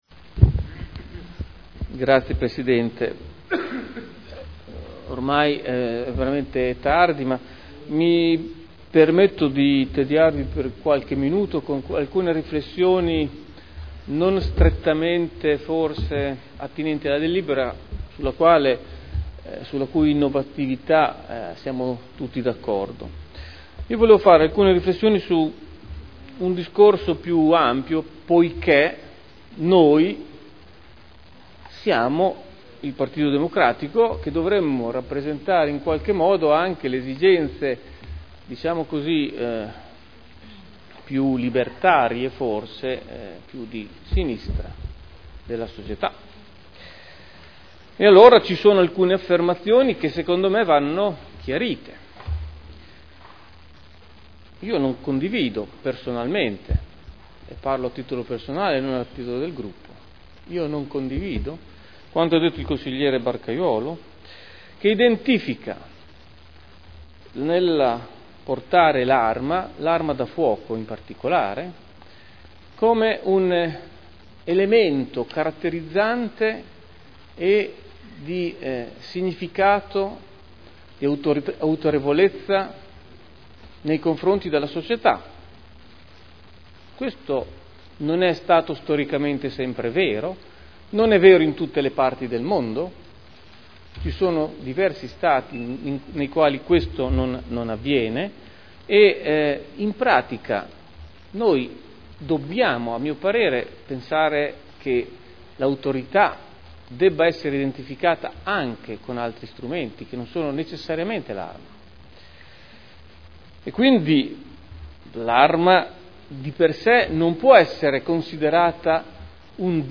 Seduta del 05/12/2011. Dibattito.